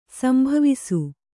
♪ sambhavisu